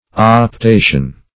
Search Result for " optation" : The Collaborative International Dictionary of English v.0.48: Optation \Op*ta"tion\, n. [L. optatio.